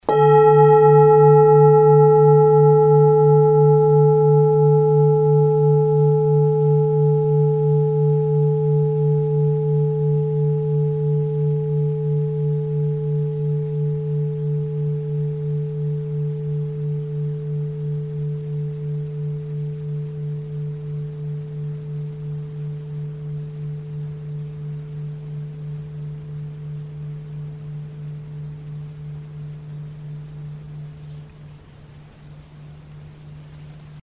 Struck or rubbed, the bowls produce a long-lasting, overtone-rich, and fine sound.
Sound sample Arhat singing bowl 1500g:
Arhat-Klangschale-1500g-Hoerprobe.mp3